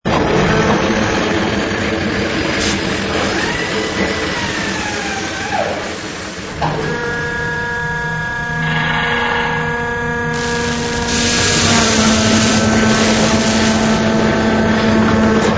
sfx_equip_wallah.wav